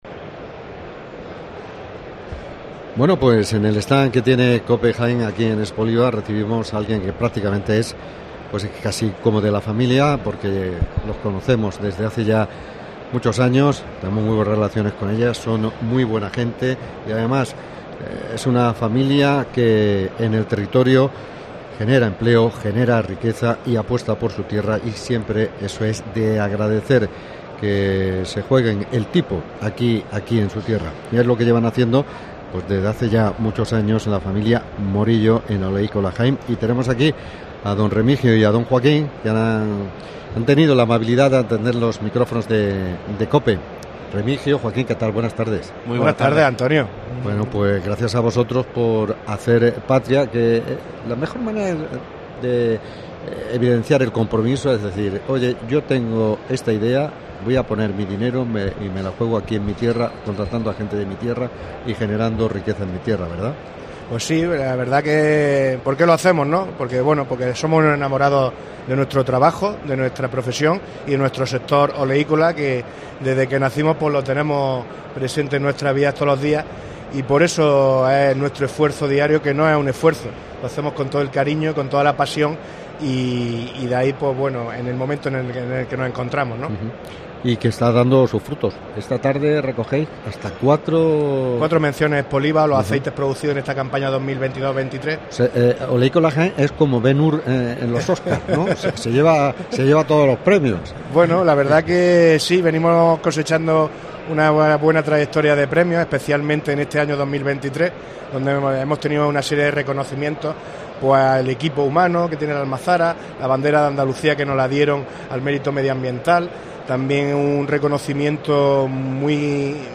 EXPOLIVA 2023 En Expoliva 2023 charlamos